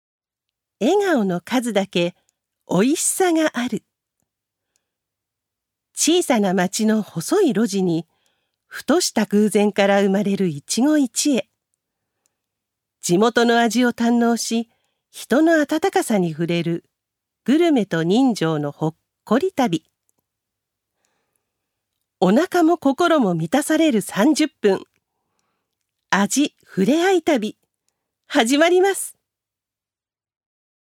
女性タレント
ナレーション１